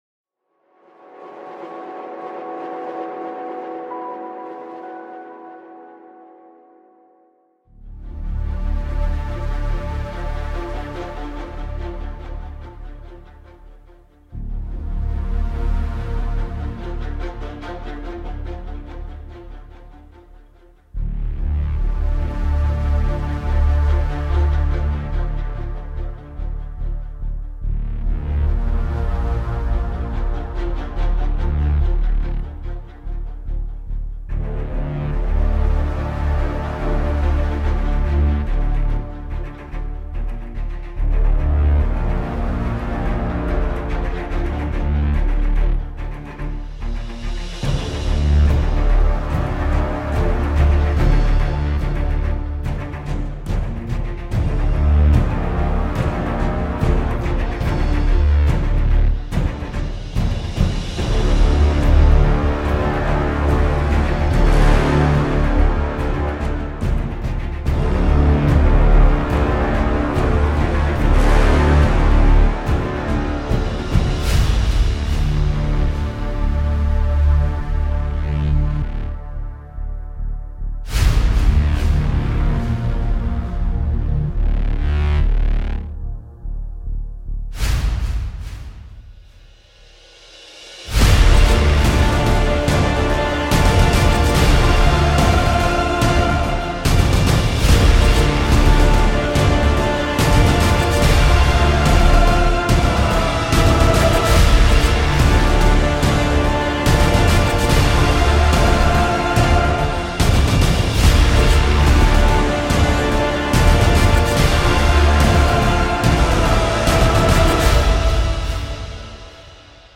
Suspense-Music-Mastermind.mp3